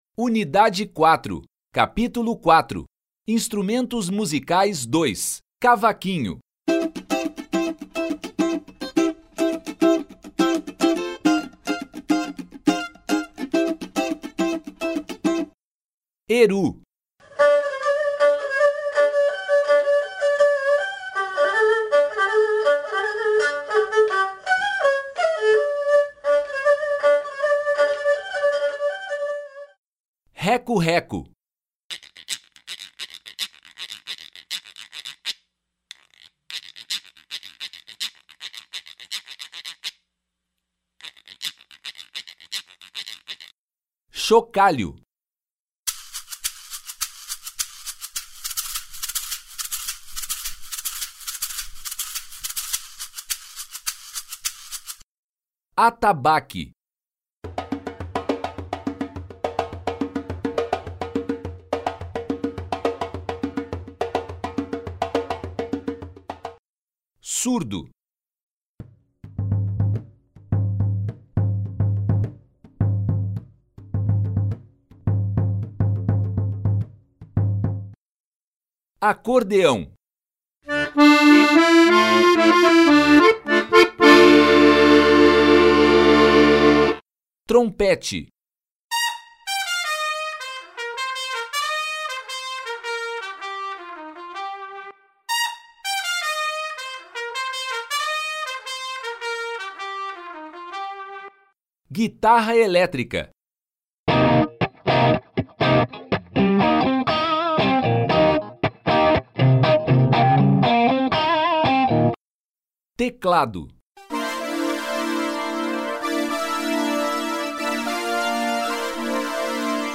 Instrumentos II